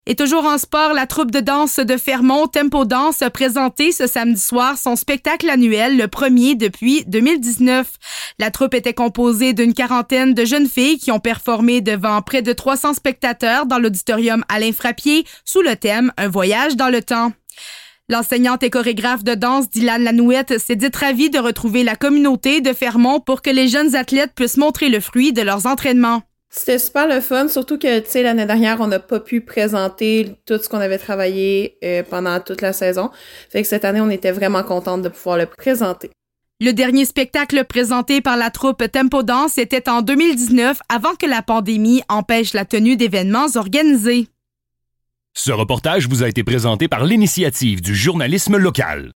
Reportage-TempoDANSE.mp3